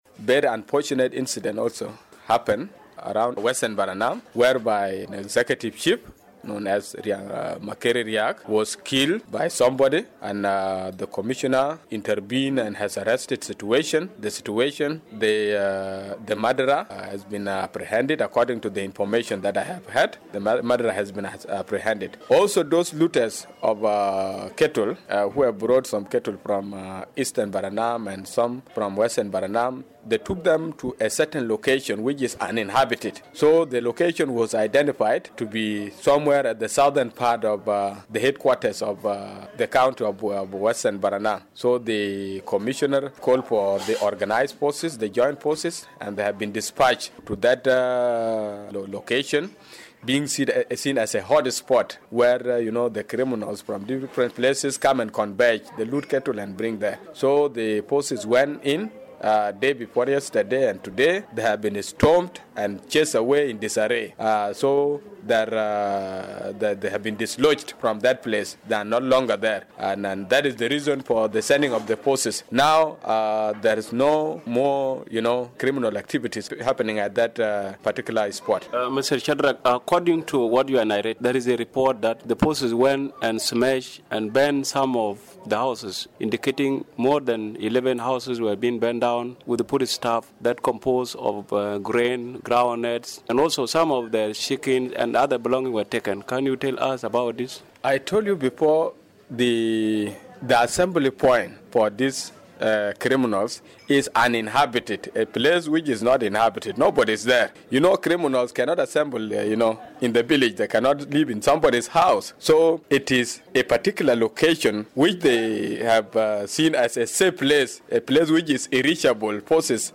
Western Lakes Minister of Information and Communication, Shadrack Bol Machok.